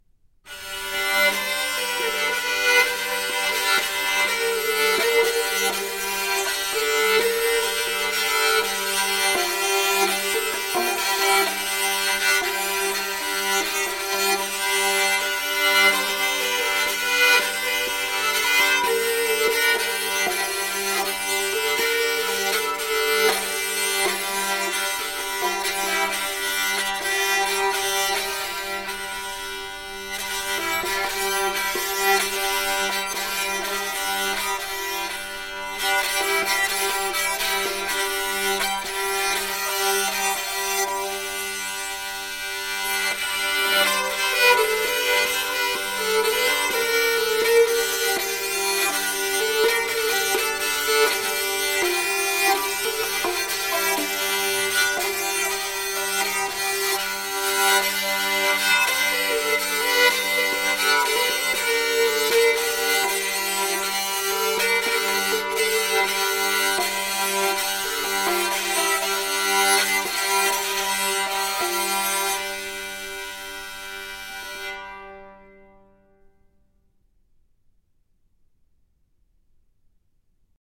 Vocal [89%] Choral [11%]